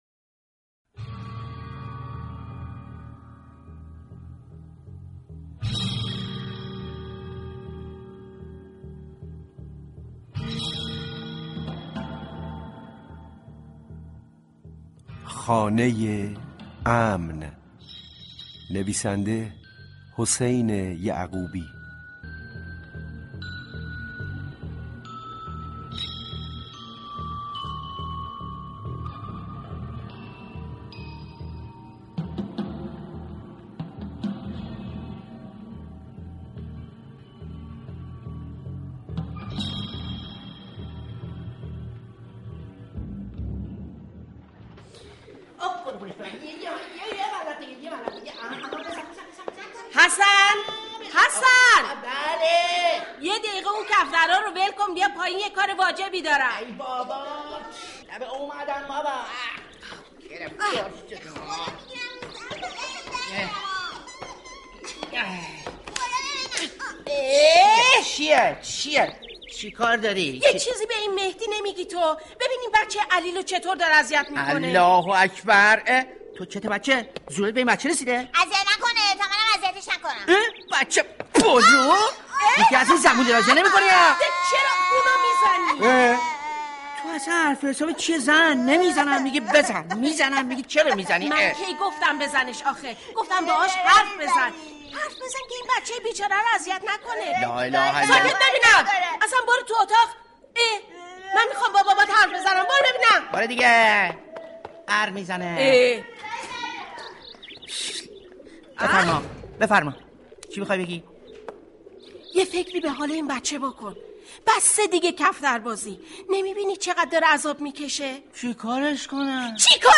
پخش سریال جدید رادیویی